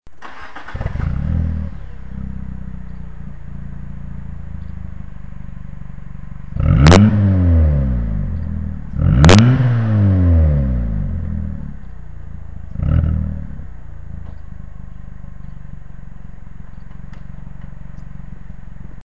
Sieht gut aus, hört sich auch beim 1.2er richtig gut an. Der brabbelt echt klasse, wie man an diesem MP3 hören kann (ist nur eine Aufnahme mit meinem MP3-Player, nix grossartiges, live natürlich viel besser).
Jetzt hört sich das Auto nach einem echten Italiener an Der Durchzug kommt mir wesentlich besser vor, ich bin immer wieder überrascht wie schnell man jetzt auf der Autobahn auf 140 ist.